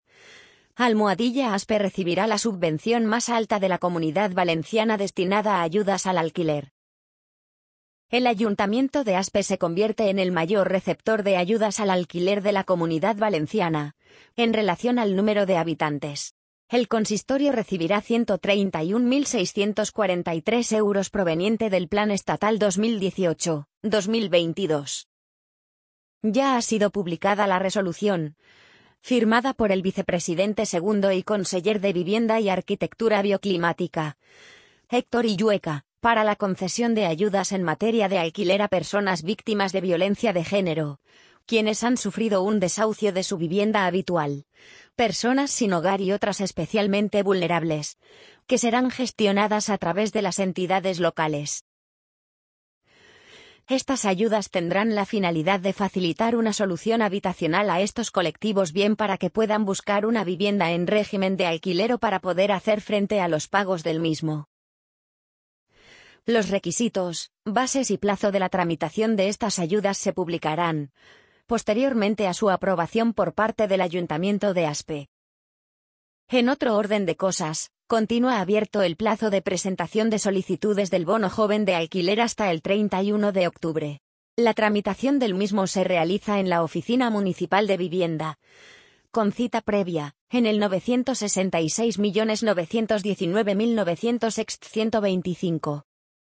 amazon_polly_60559.mp3